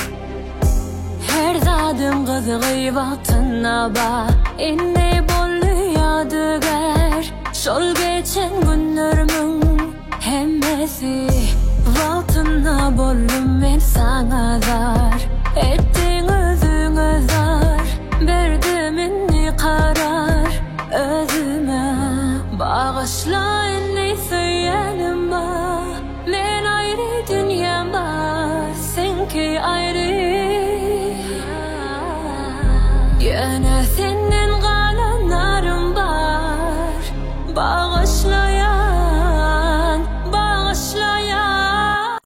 Узбекские песни Слушали